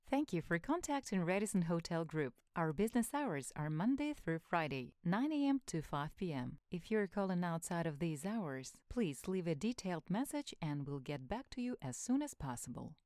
Жен, Автоответчик/Средний
Конденсаторный микрофон Behringer B-1, звуковая карта Audient Evo 4